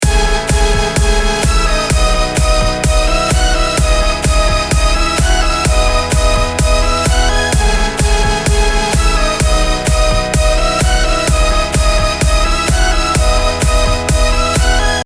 HYPEは使わずに制作したトラック